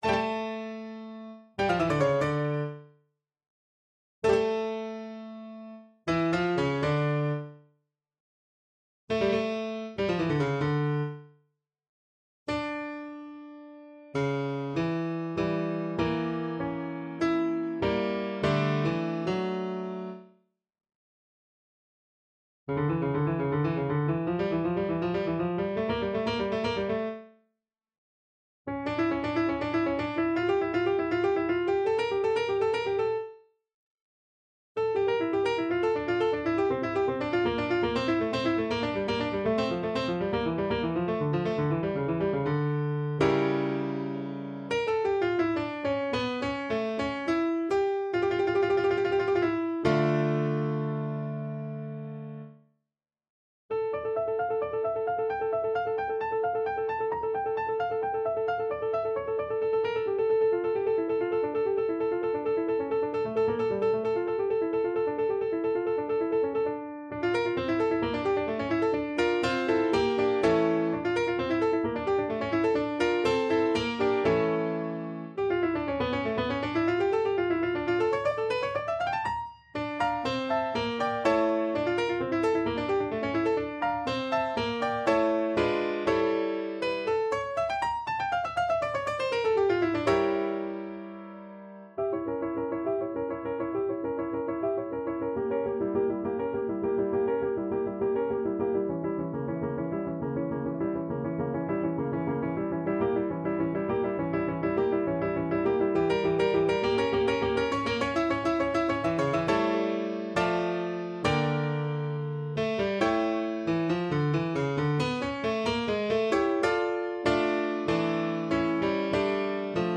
for Violin and Viola (version 2)
» 442Hz